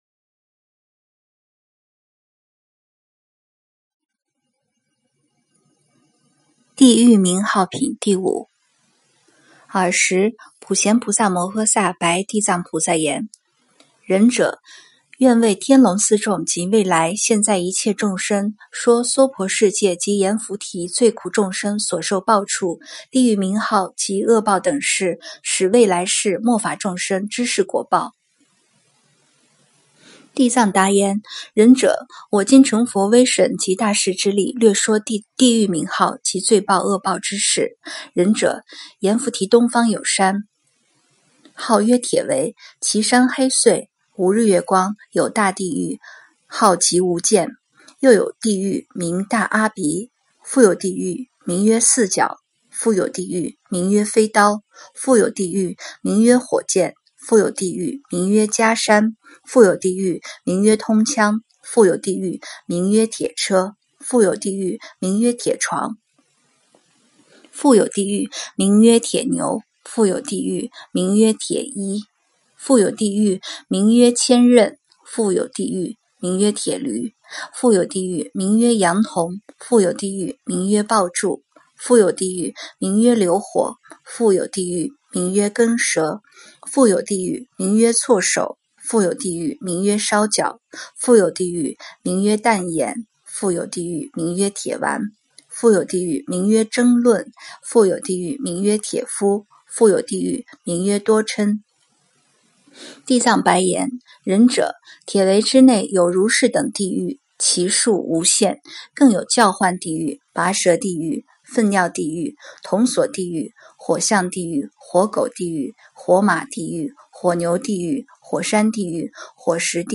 经忏
佛音 经忏 佛教音乐 返回列表 上一篇： 《妙法莲华经》分别功德品第十七--佚名 下一篇： 《妙法莲华经》常不轻菩萨品第二十--佚名 相关文章 普贤菩萨行愿品--普寿寺 普贤菩萨行愿品--普寿寺...